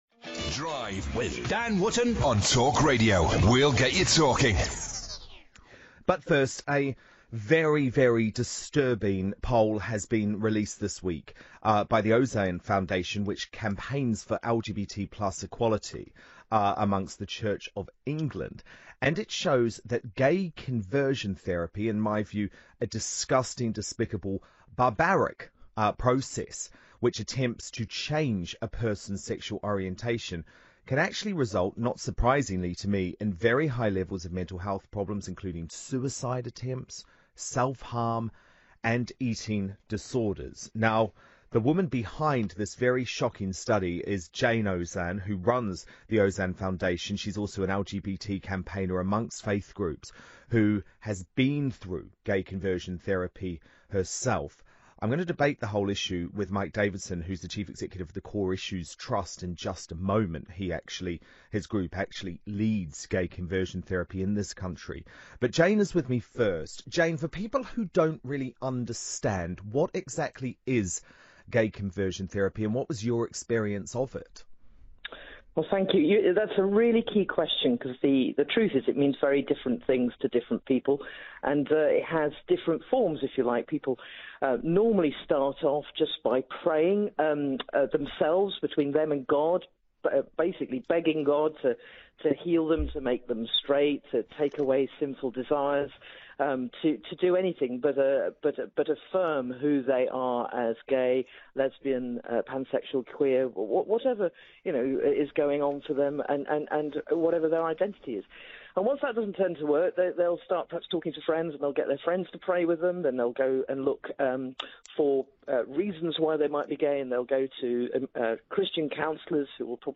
Talk Radio presenter Dan Wootton talks to Jayne Ozanne